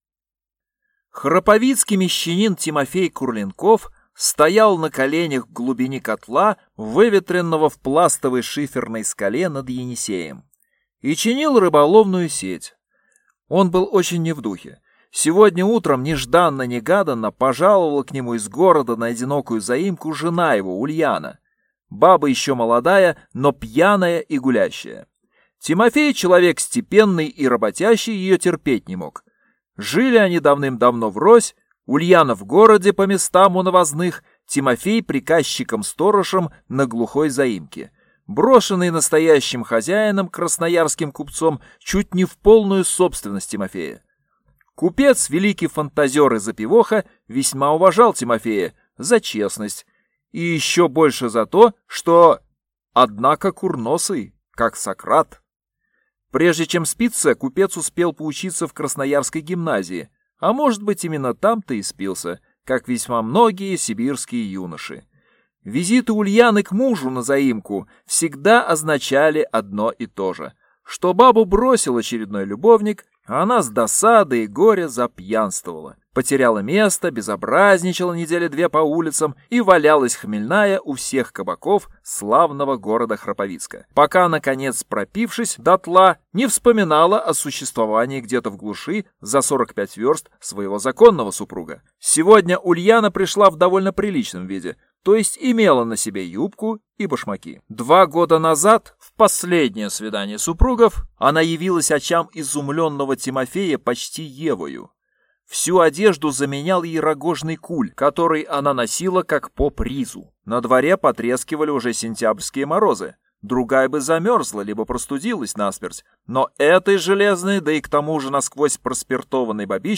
Аудиокнига Побег Лизы Басовой | Библиотека аудиокниг